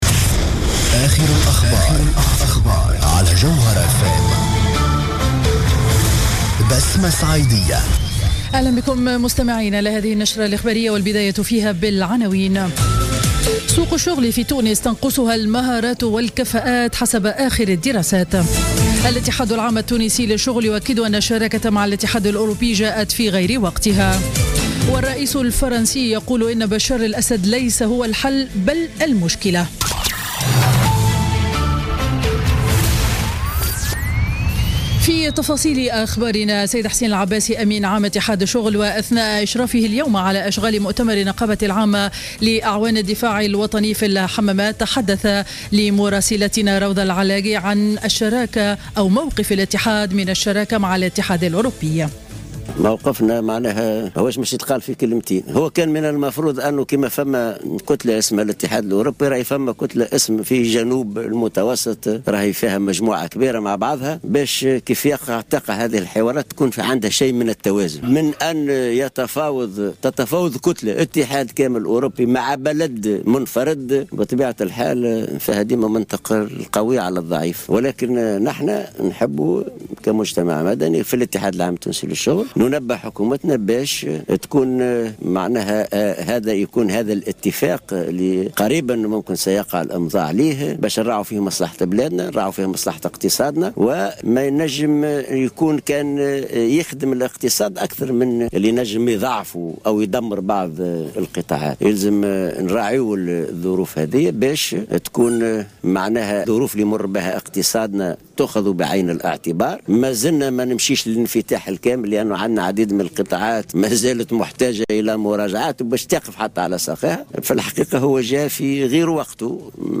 نشرة أخبار منتصف النهار ليوم الجمعة 23 أكتوبر 2015